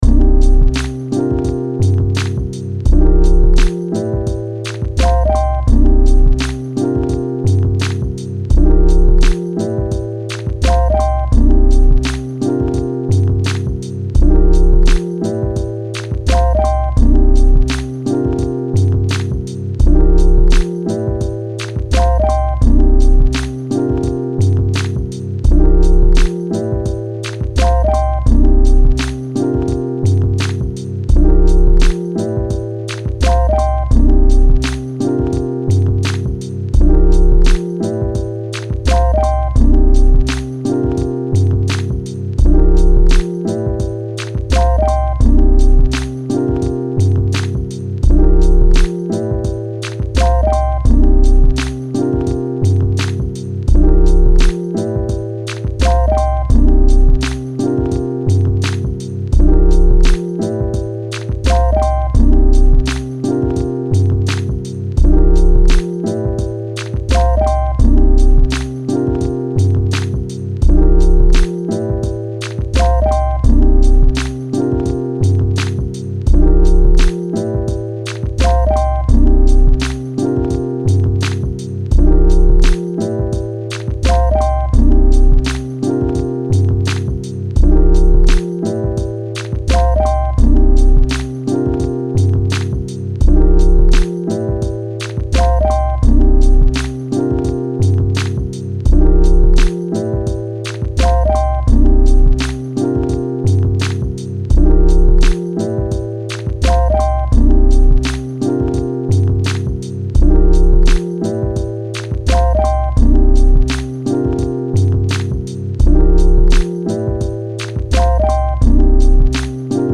Home > Music > Beats > Smooth > Medium > Laid Back